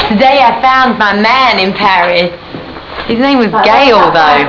(all files here are in *.wav format. Recorded at 11025 Hz in 8 bit mono)